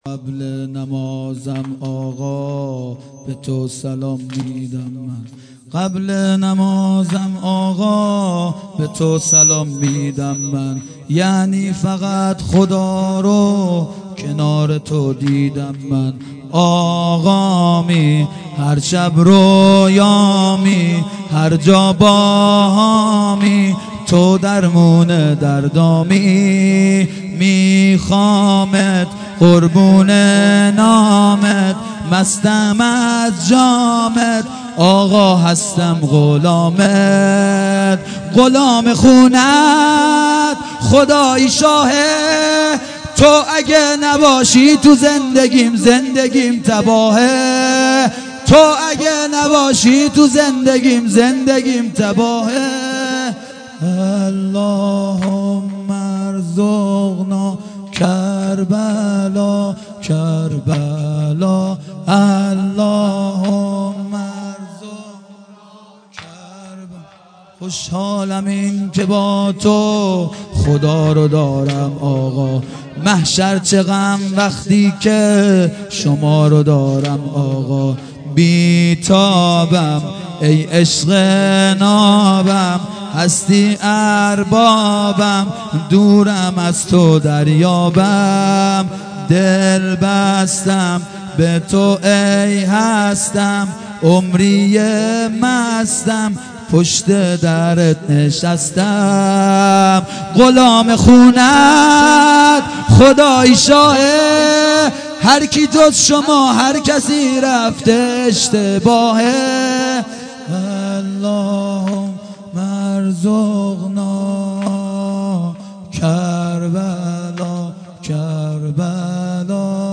شب سوم محرم 96